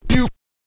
scratch_kit01_04.wav